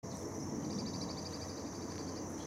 Carpintero Bataraz Chico (Veniliornis mixtus)
Nombre en inglés: Checkered Woodpecker
Fase de la vida: Adulto
Localidad o área protegida: Reserva Ecológica Costanera Sur (RECS)
Condición: Silvestre
Certeza: Vocalización Grabada